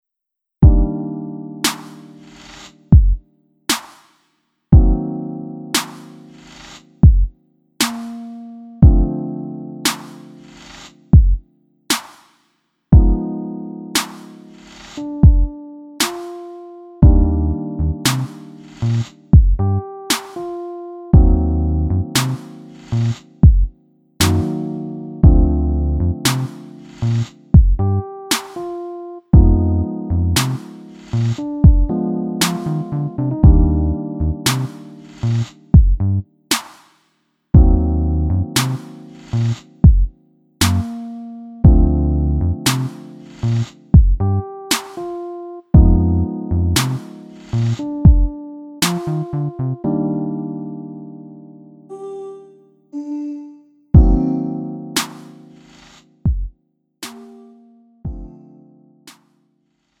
음정 원키 4:59
장르 구분 Lite MR